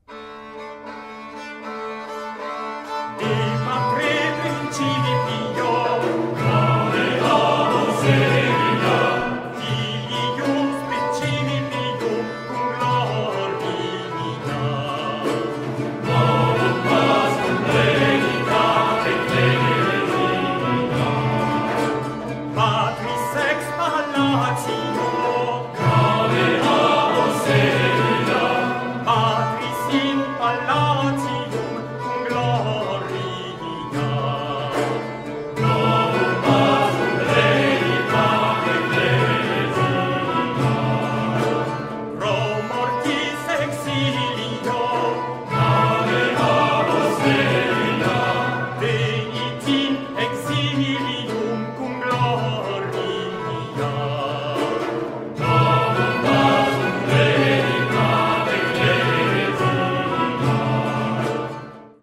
Mediceo raccoglie 60 brevi antiche composizioni latine di uso liturgico, dette rondelli o rotundelli, che nella forma più semplice sono quartine di versi intonati secondo lo schema AABB, dove la ripetizione è reponsoriale.
(Musica cathedralis, La Maurache (1989)